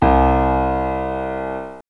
SAMPLES : Piano
piano nē 1
piano1.mp3